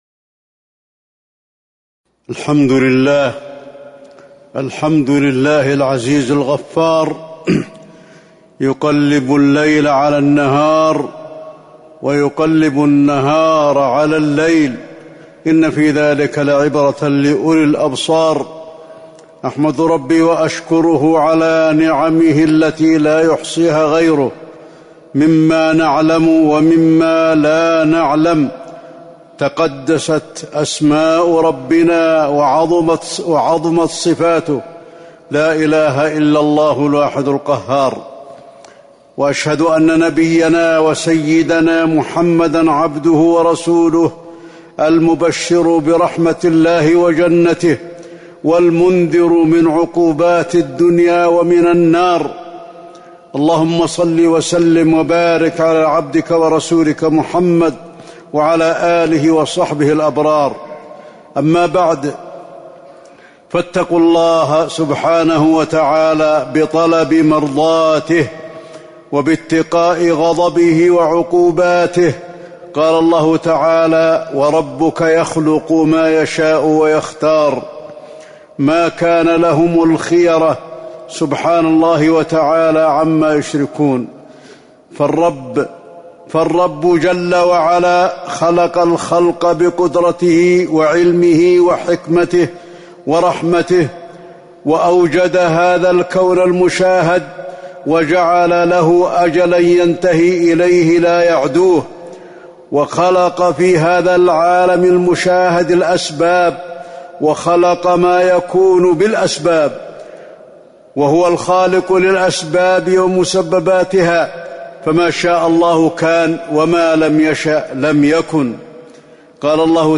تاريخ النشر ١٠ صفر ١٤٤٣ هـ المكان: المسجد النبوي الشيخ: فضيلة الشيخ د. علي بن عبدالرحمن الحذيفي فضيلة الشيخ د. علي بن عبدالرحمن الحذيفي كرم الله ونعمه على عبده